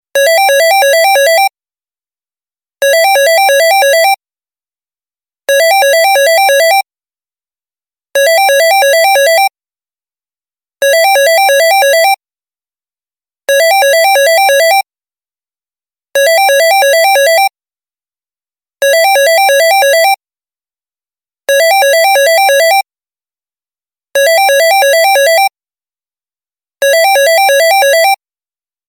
telefonnyi-zvonok_24632.mp3